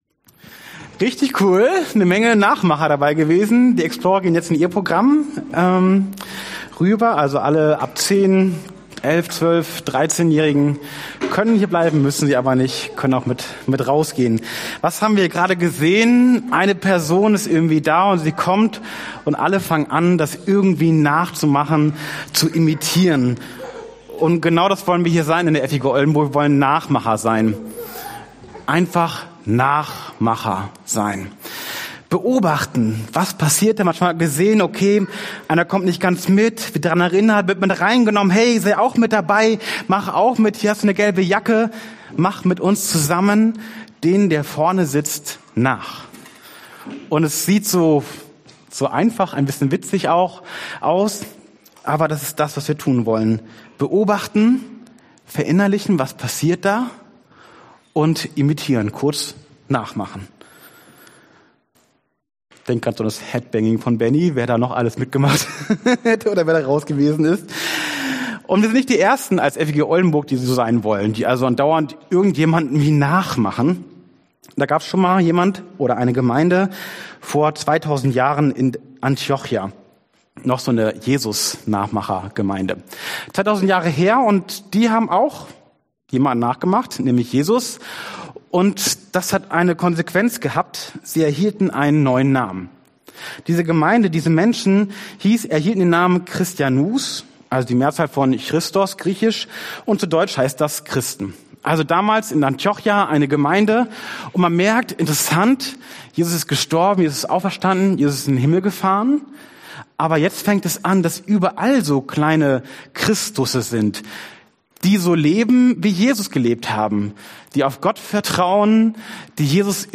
wenn's gut werden soll Dienstart: Predigt « JESUS